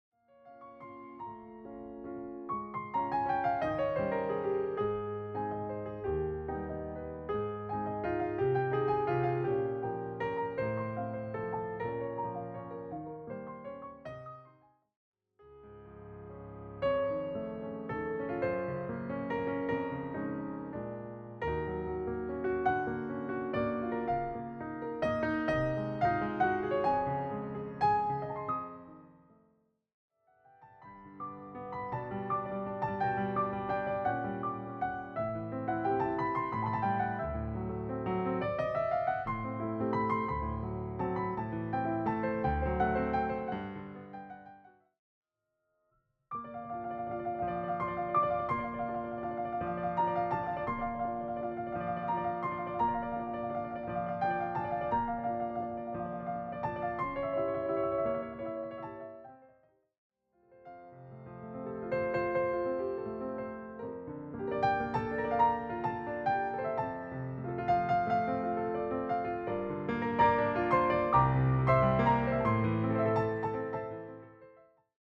all reimagined through solo piano.